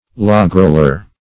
Logroller \Log"roll`er\, n.